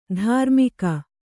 ♪ dhārmika